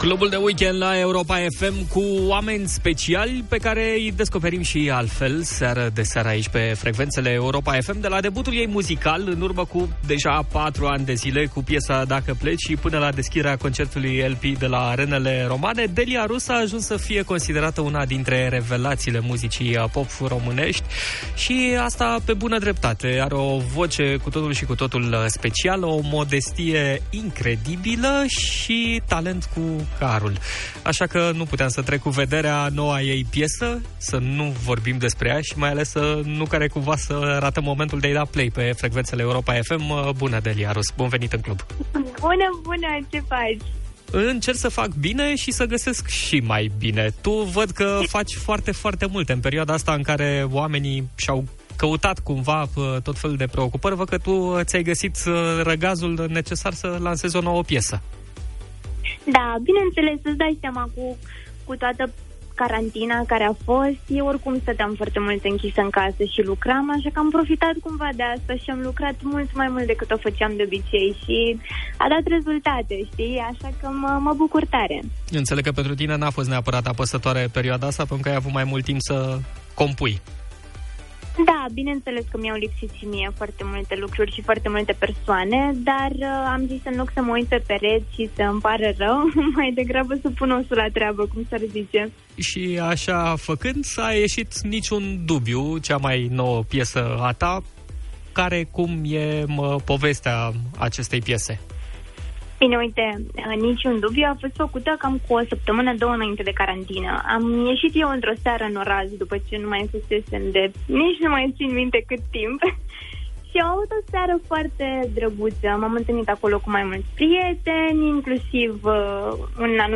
în direct în cel mai mare club din România, Clubul de Weekend, deschis vineri seara la Europa FM